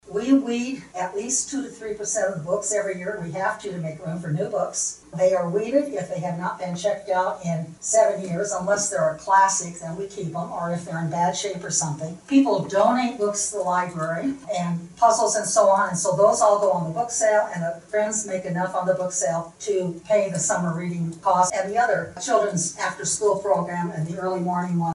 📖 The Lake View Public Library shared its annual report during the City of Lake View City Council meeting this past Monday.